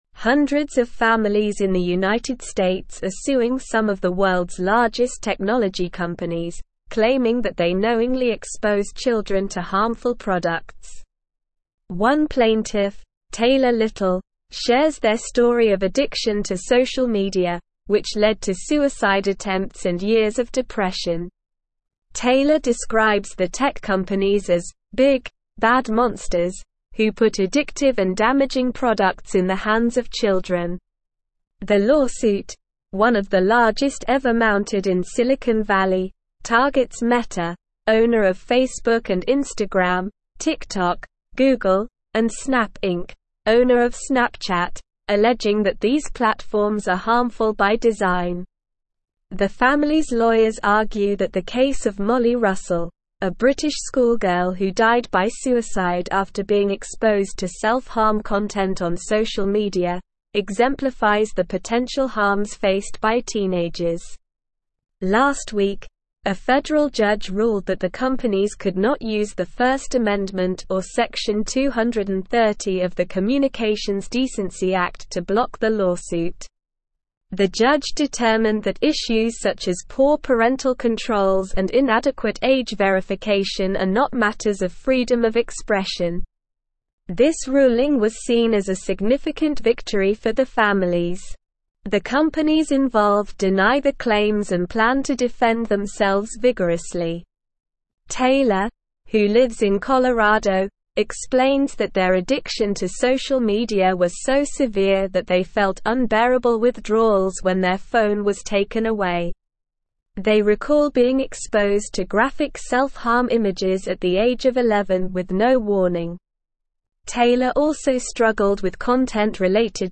Slow
English-Newsroom-Advanced-SLOW-Reading-Tech-Giants-Sued-for-Exposing-Children-to-Harm.mp3